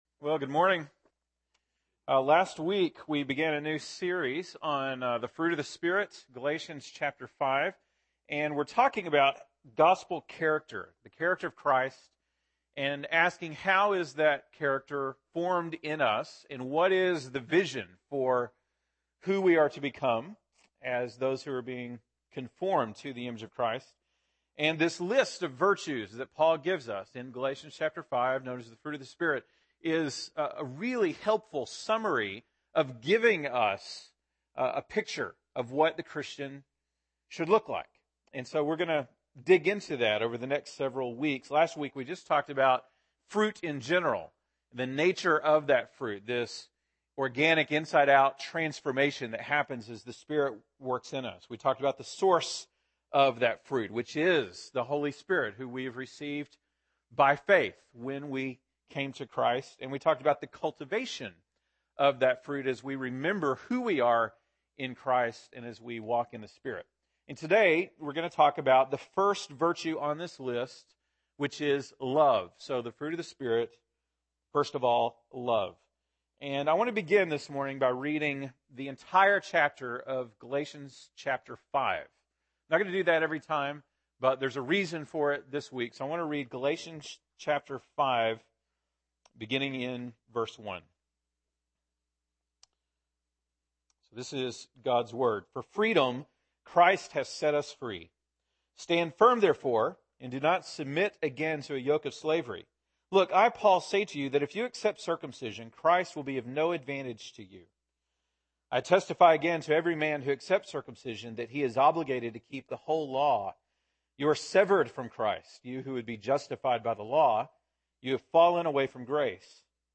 February 1, 2015 (Sunday Morning)